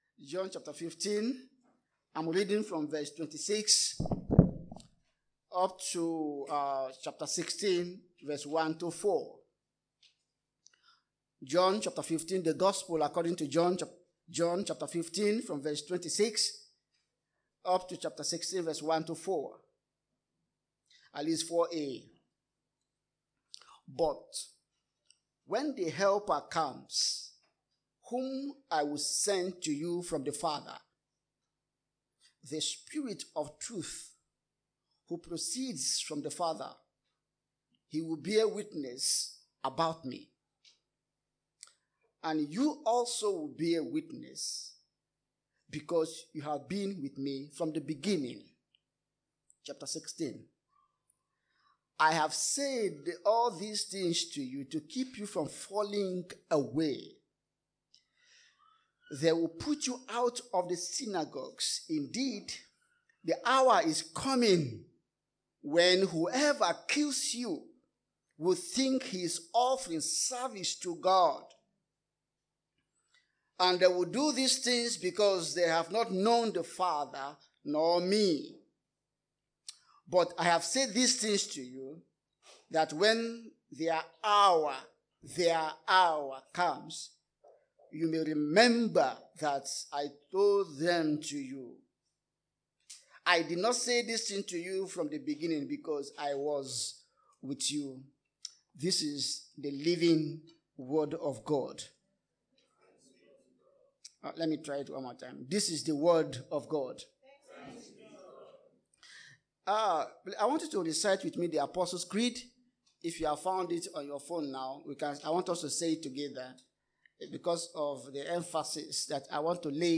TBC | Sermon